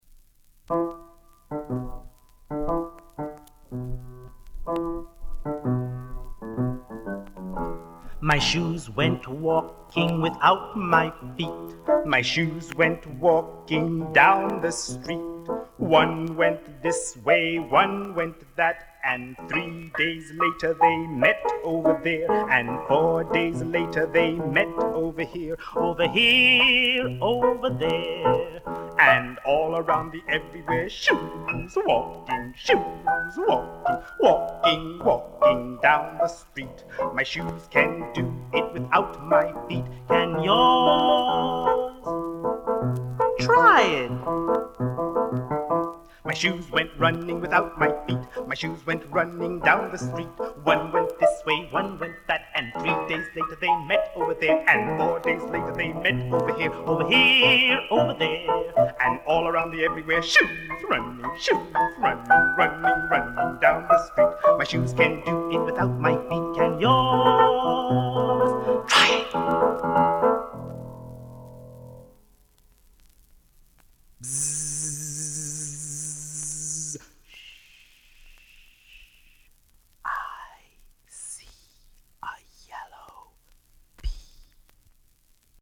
Hope you enjoy it, despite the severe crackles and pops.